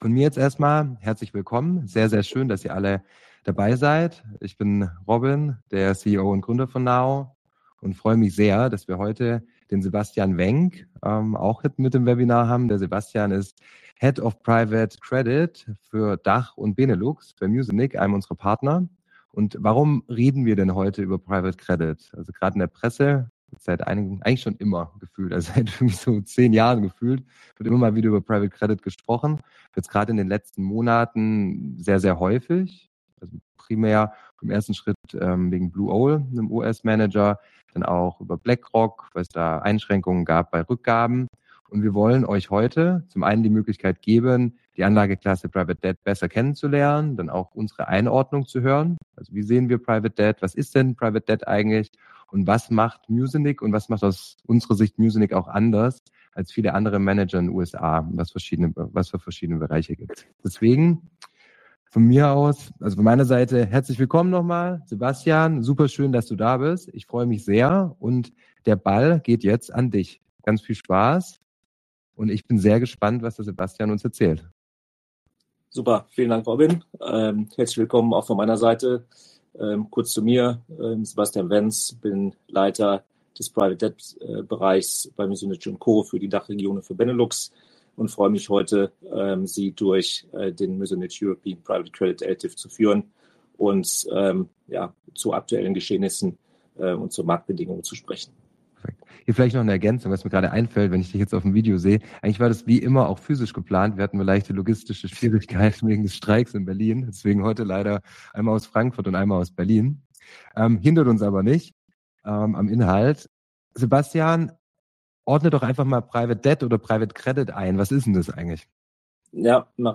Gemeinsam mit Muzinich & Co., einem der führenden Spezialisten für Unternehmenskredite mit über 35 Jahren Erfahrung, geben wir Dir in einem Live-Webinar einen tiefen Einblick in diese spannende Alternative zu klassischen An...